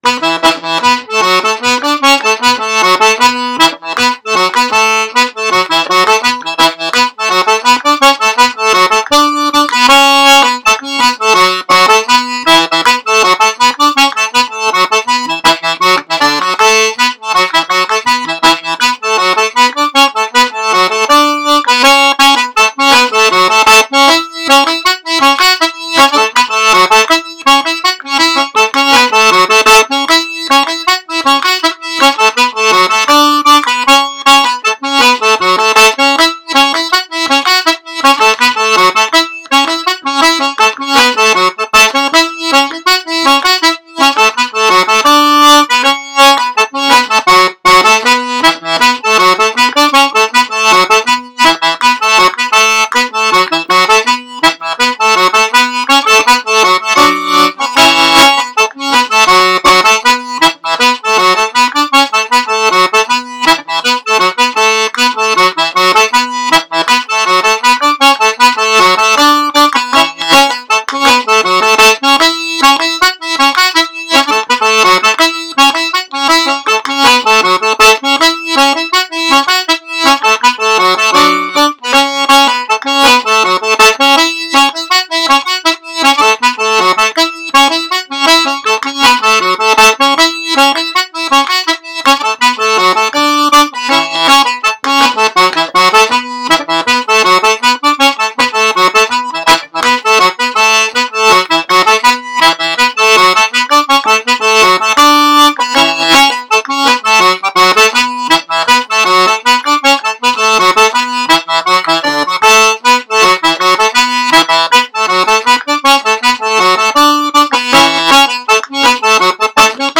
Tune | Reel | New Mown Meadow – Sean-nós & Set Dance with Maldon
Nrw-Mown-Meadows-80-bpm.m4a